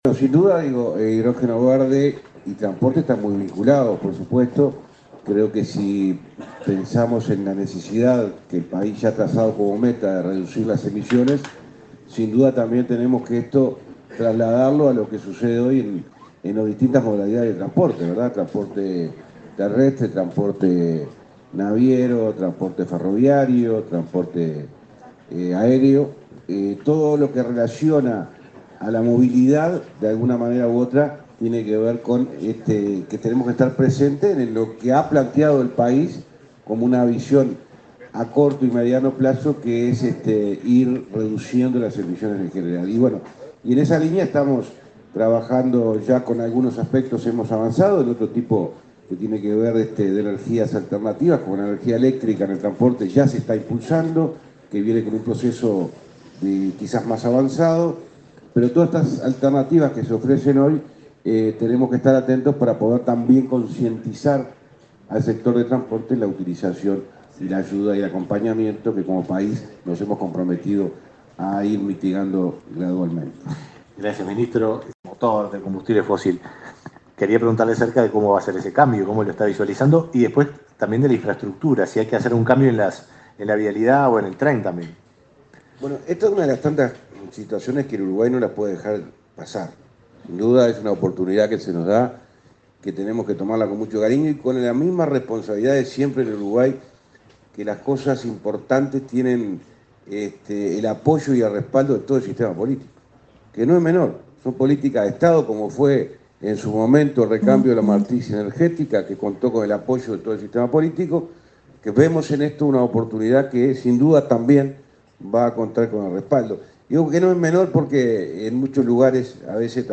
Palabras del ministro de Transporte, José Luis Falero
El ministro de Transporte, José Luis Falero, participó este jueves 16 en el Club de Golf, del V Foro Económico, que trató sobre la descarbonización